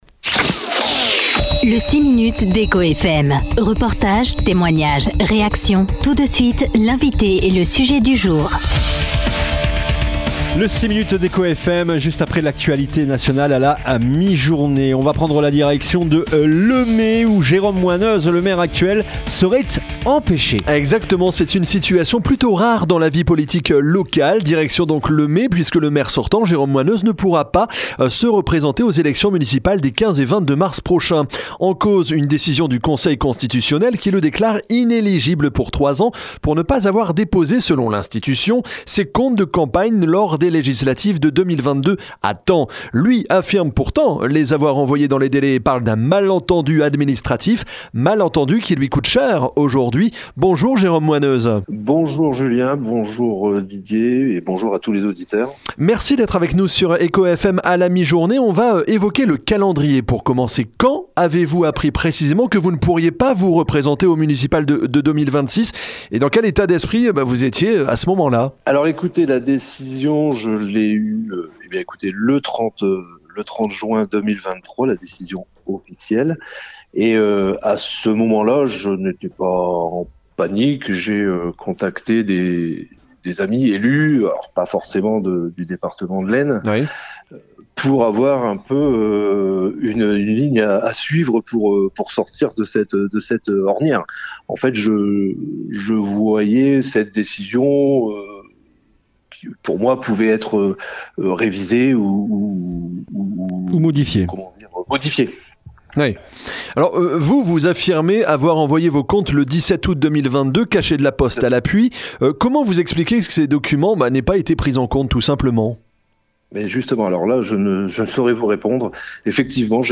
Ecoutez les explications de Jérôme Moineuse.